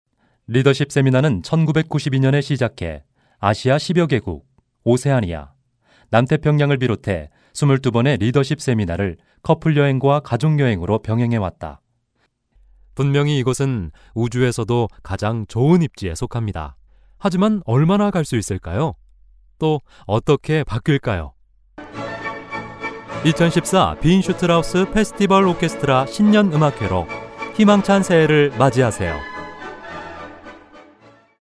KOREAN MALE VOICES
male